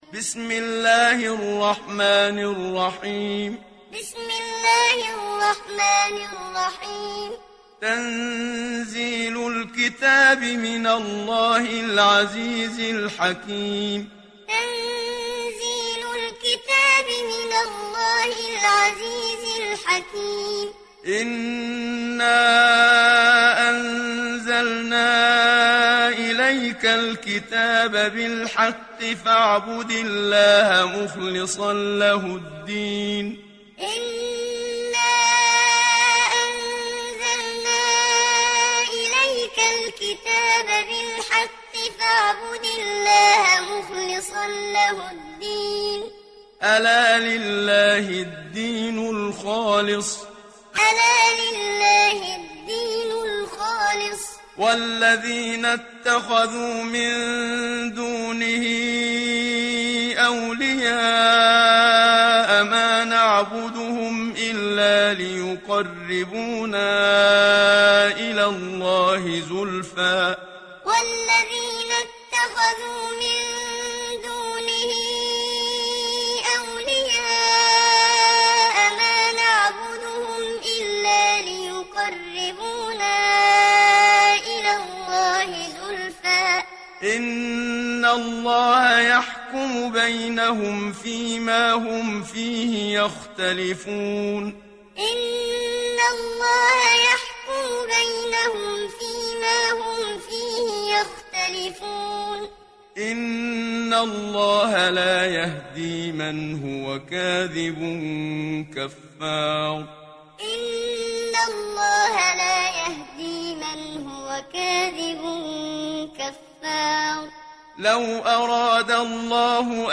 İndir Zümer Suresi Muhammad Siddiq Minshawi Muallim